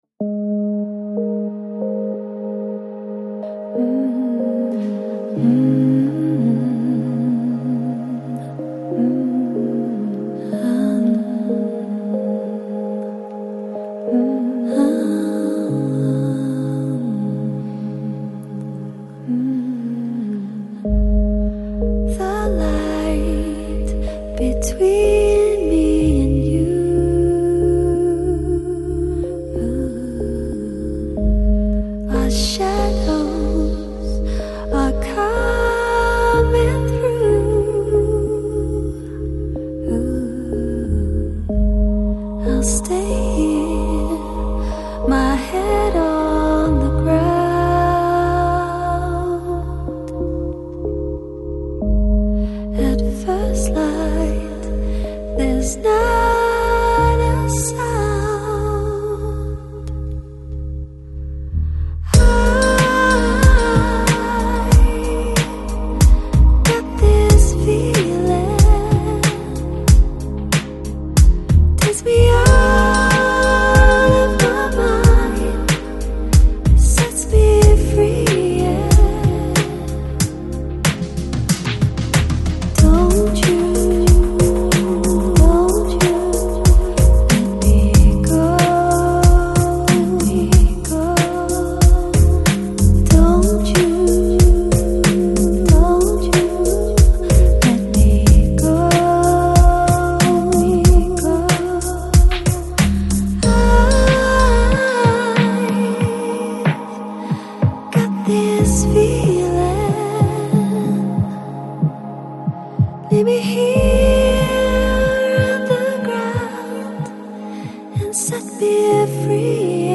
Жанр: Downtempo, Deep House